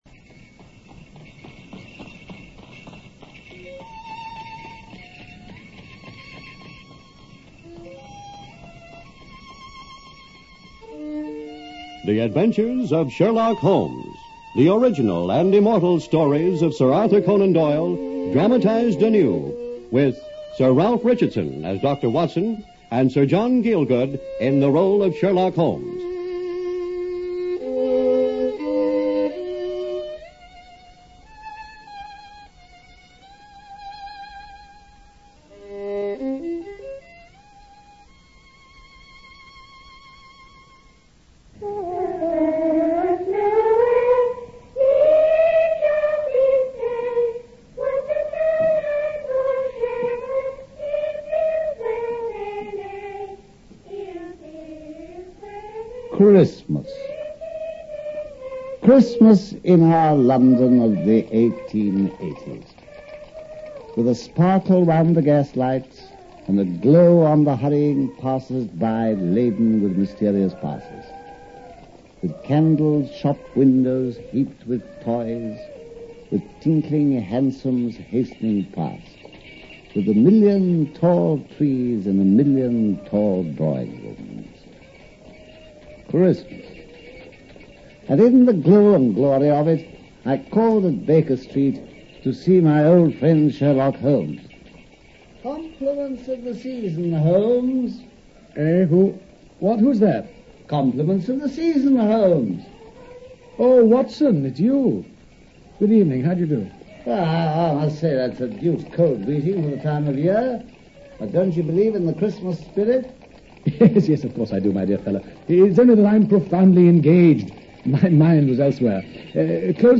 Sir John Gielgud (Sherlock Holmes) and Sir Ralph Richardson (Dr. Watson) star in this old-time radio show dramatization of Sir Arthur Conan Doyles Sherlock Holmes detective stories.